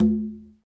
Quinto-HitN_v1_rr1_Sum.wav